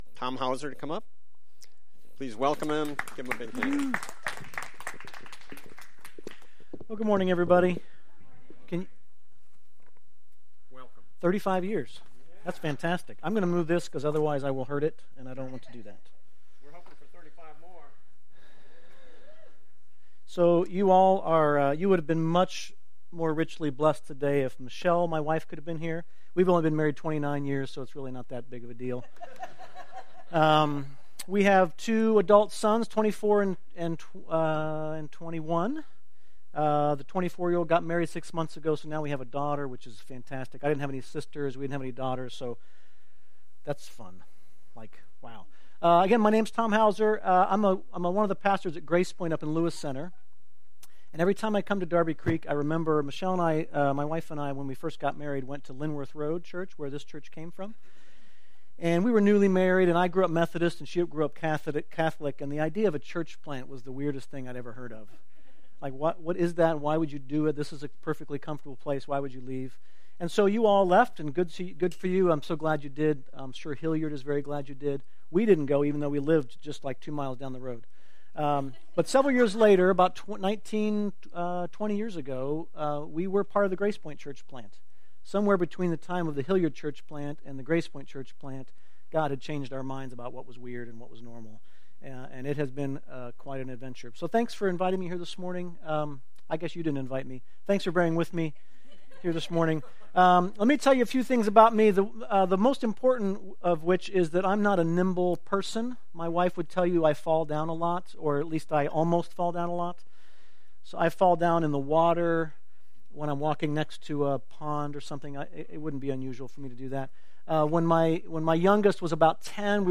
Sermons that are not part of a series